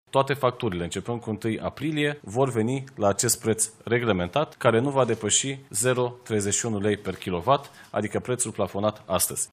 Oamenii nu trebuie să facă absolut nimic, trebuie doar să aștepte să vină factura, a declarat ministrul Energiei, Bogdan Ivan, după ședința de guvern.